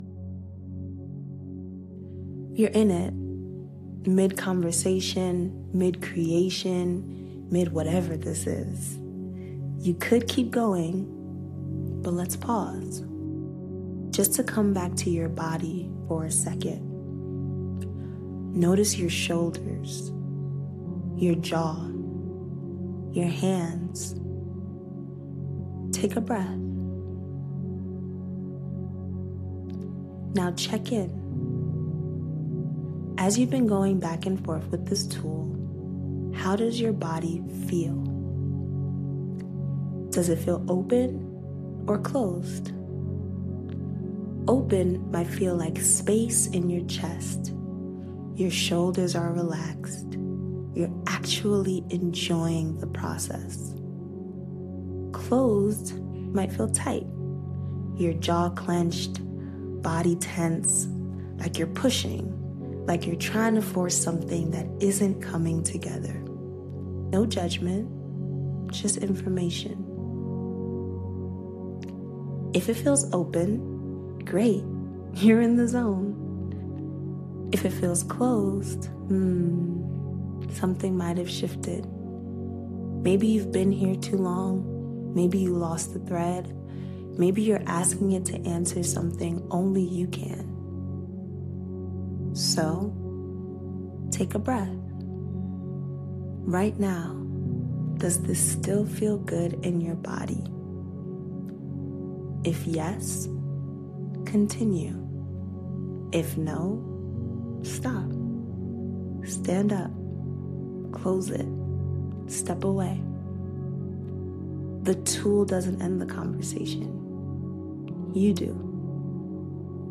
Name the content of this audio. Guided Practice: